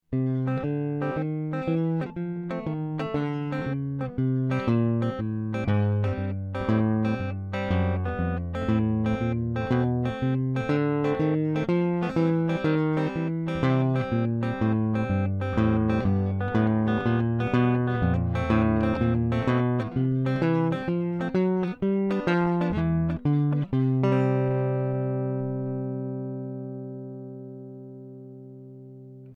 Шагающий бас на гитаре